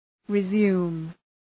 Προφορά
{rı’zu:m}